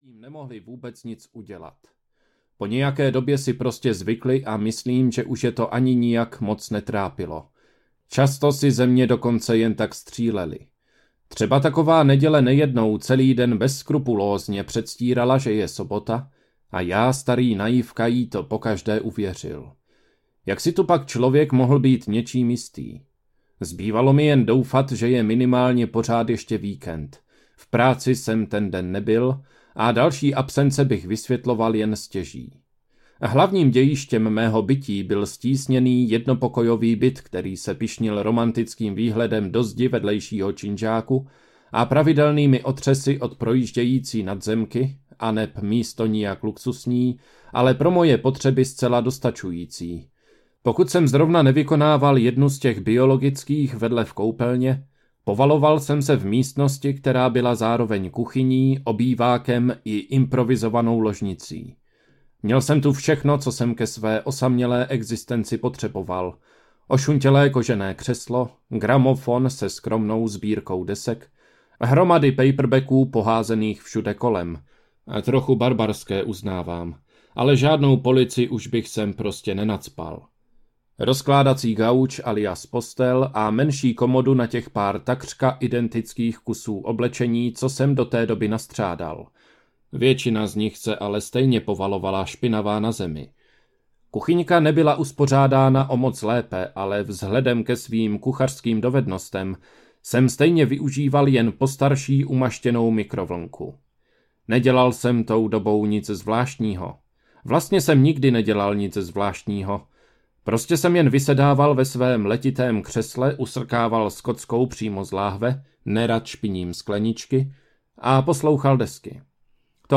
Mortificatio audiokniha
Ukázka z knihy